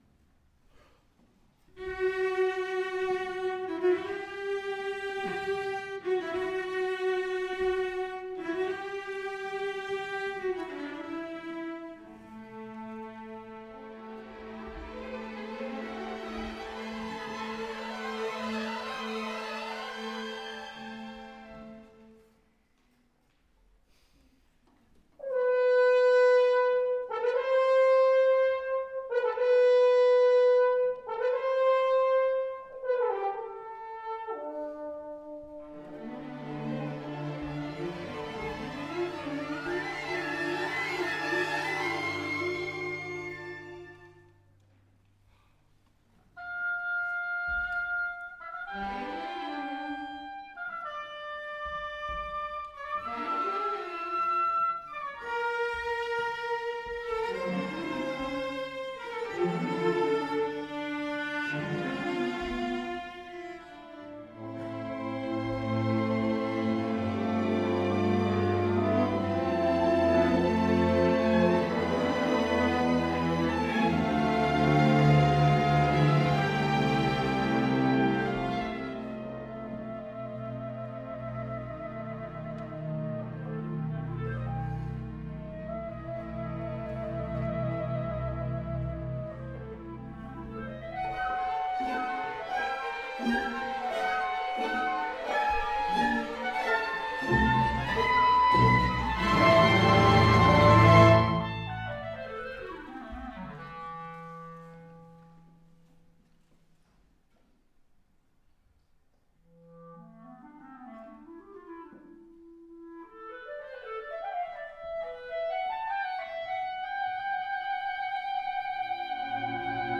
Orchestre symphonique du CRR 93 (2018)
- un poème symphonique de Zoltan Kodaly, compositeur hongrois 1882-1967.